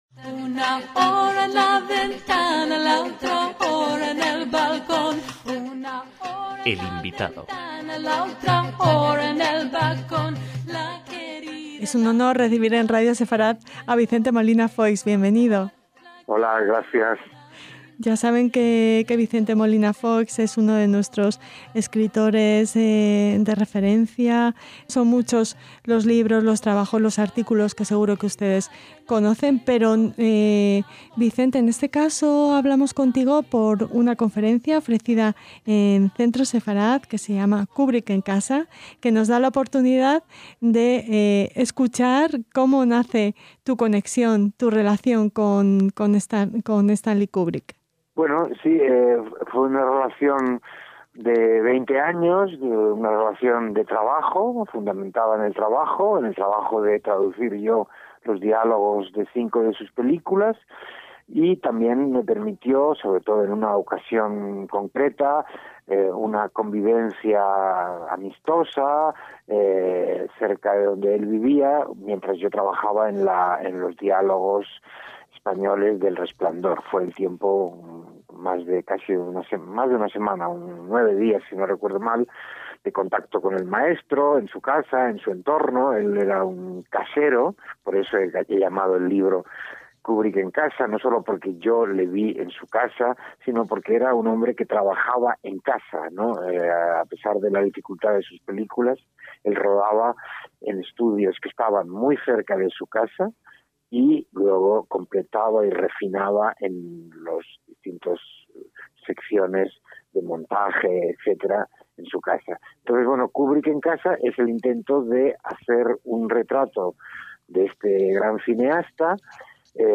El INVITADO DEL CENTRO SEFARAD-ISRAEL - A 50 años del estreno de La naranja mecánica reponemos esta entrevista con el escritor y cineasta Vicente Molina Foix, uno de los pocos españoles que tuvieron la suerte de convivir con Stanley Kubrick además en la propia casa del genial director.